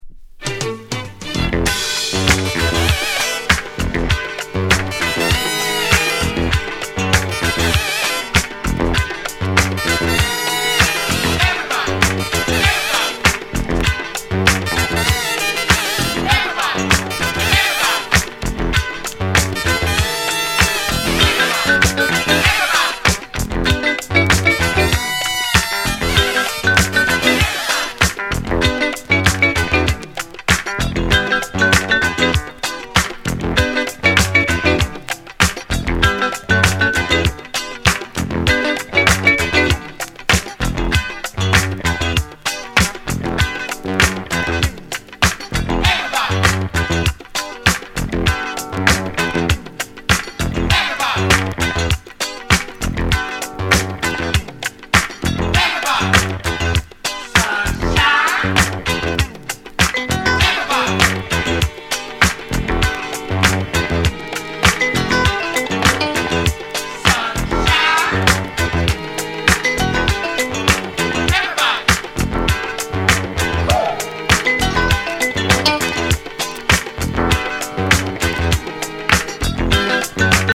Genre: Soul